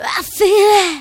• Samples de  Voz